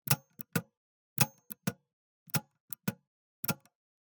Движение рычага или кнопки 4